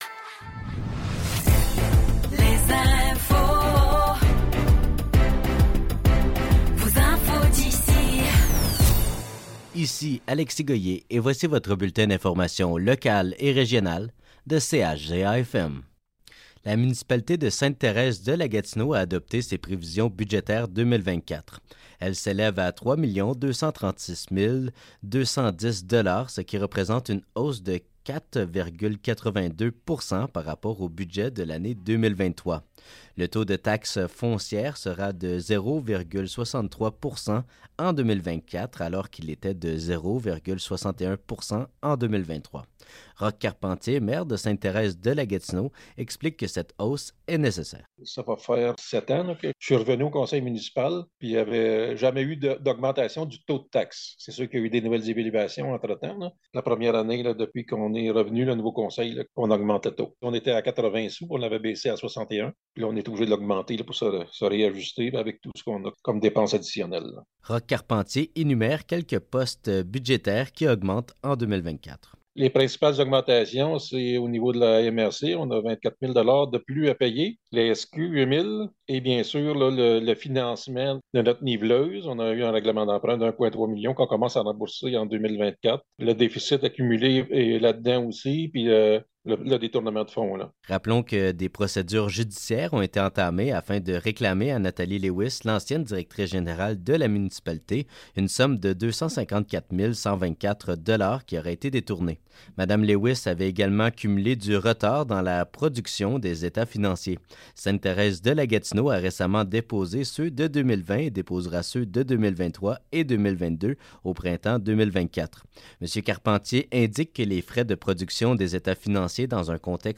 Nouvelles locales - 22 décembre 2023 - 12 h